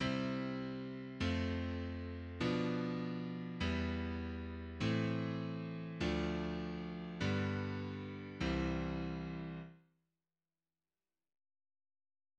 Круговая последовательность в до мажоре